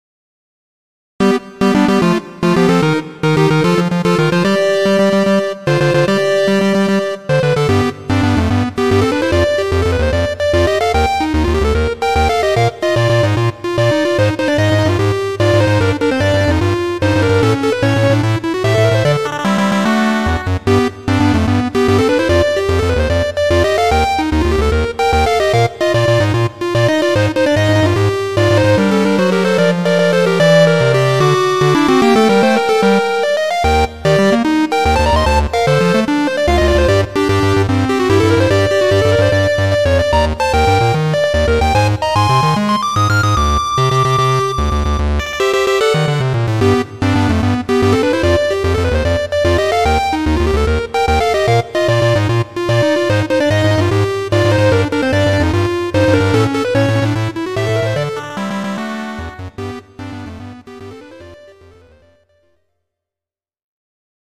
３和音なのになんとも素敵な曲。GS音源。